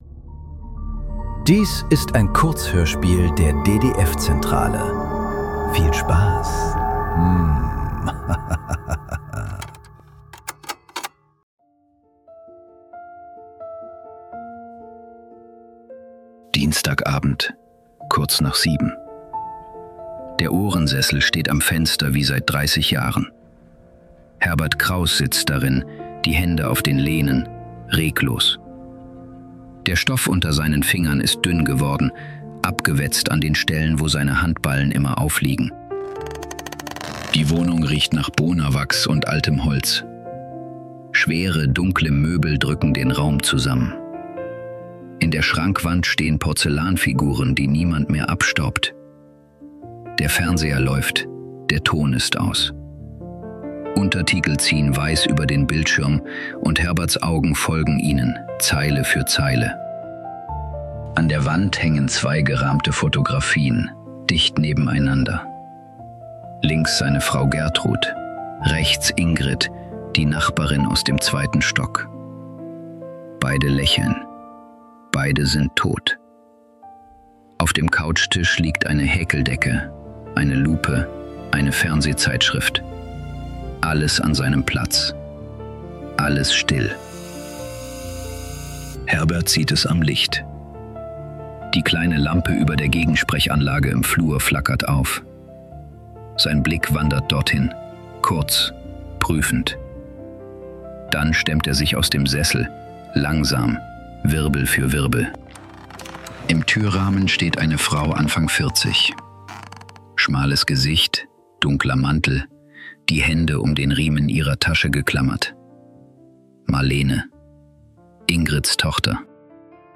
47 Sekunden ~ Nachklang. Kurzhörspiele.